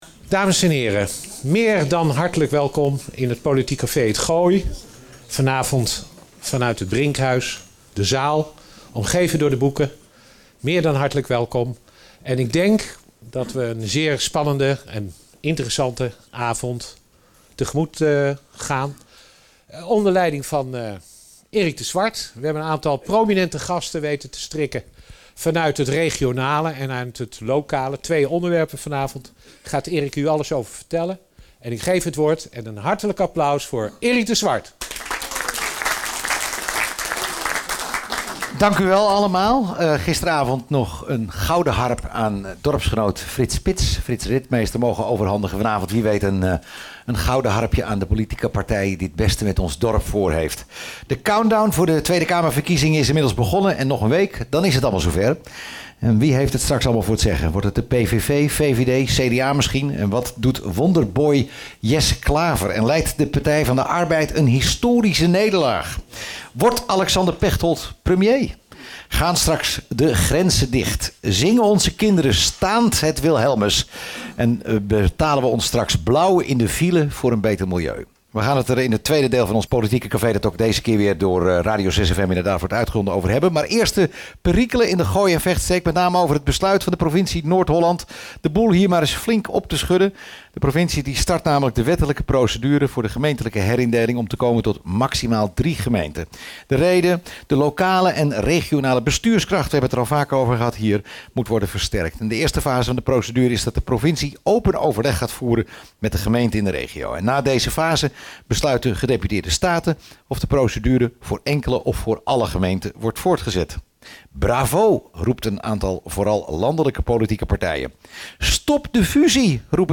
In het Brinkhuis in Laren werd dinsdag 7 maart gedebatteerd over de fusieplannen van de provincie Noord-Holland voor de gemeenten in de Gooi en Vechtstreek en de landelijke verkiezingen op 15 maart. Het debat werd geleid door Erik de Zwart.